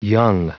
Prononciation du mot young en anglais (fichier audio)
Prononciation du mot : young